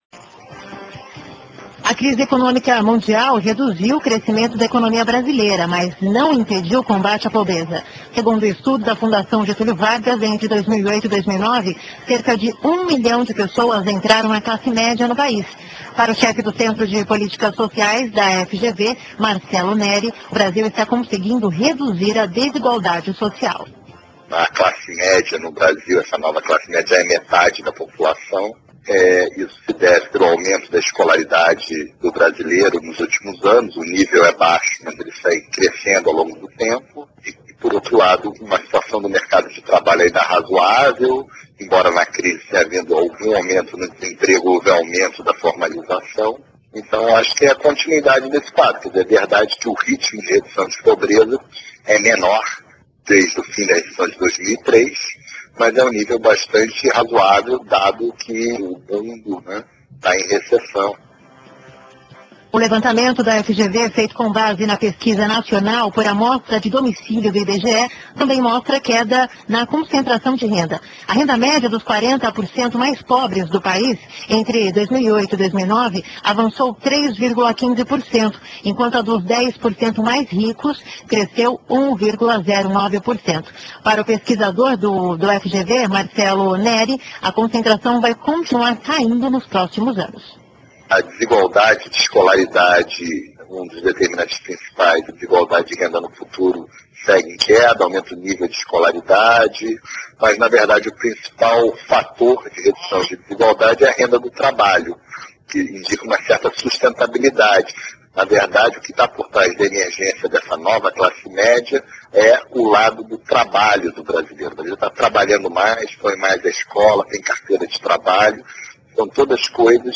Rádio Band News - SP Mídia: Rádio